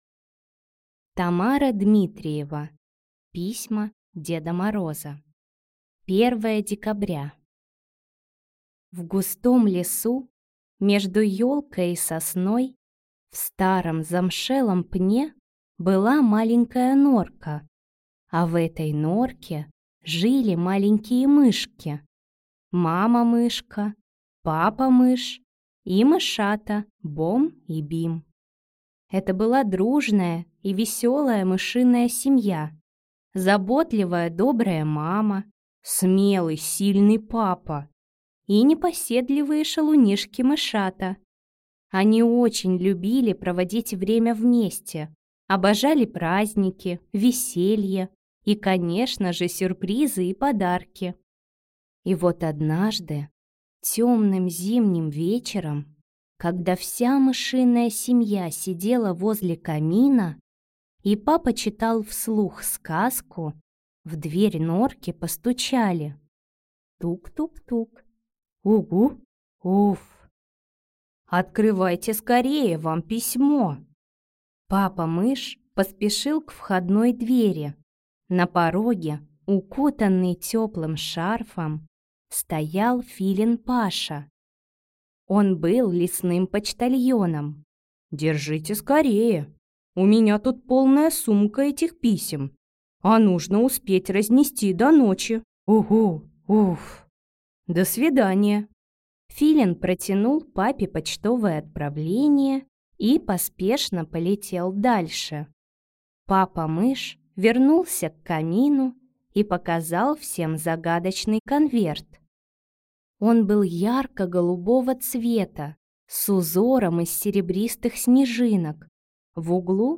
Аудиокнига Письма Деда Мороза | Библиотека аудиокниг